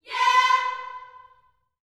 YEAH C 5C.wav